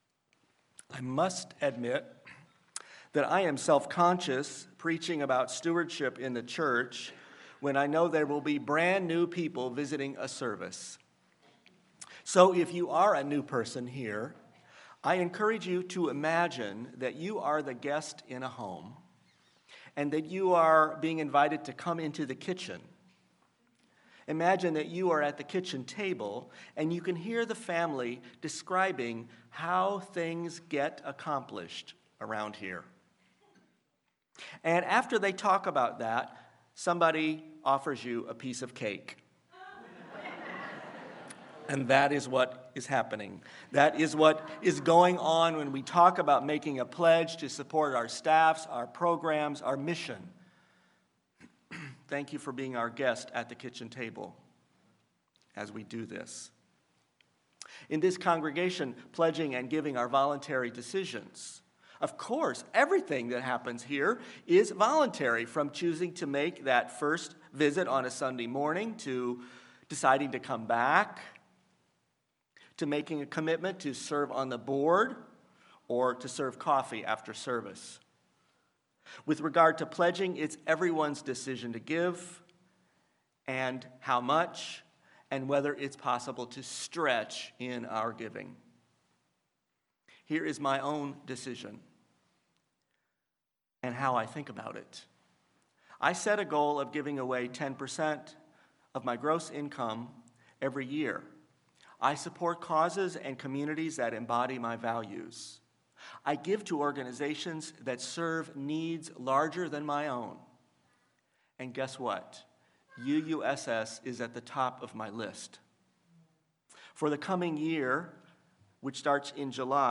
Sermon-Powering-our-Commitment.mp3